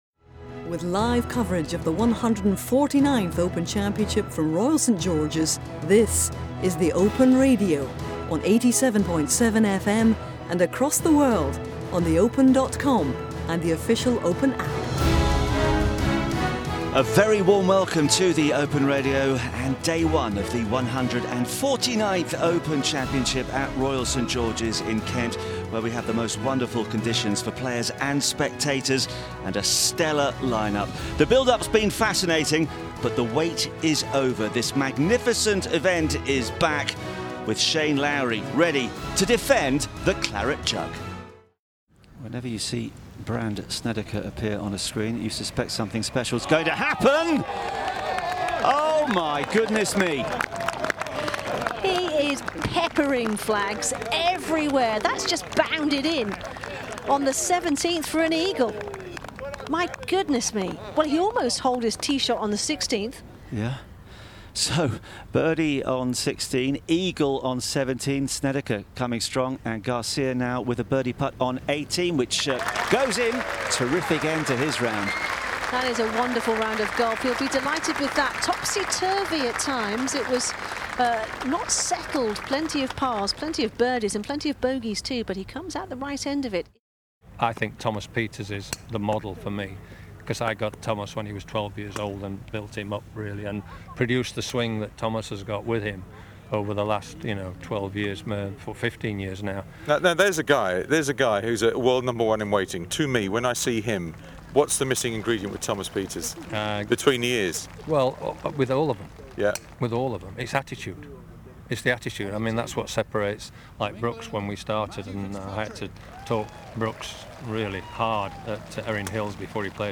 an international team of commentators and former players bring you worldwide audio coverage of the Championship, including live commentary, analysis and colourful interviews from across the course.